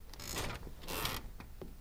Bed Squeaks
Bed Small Squeak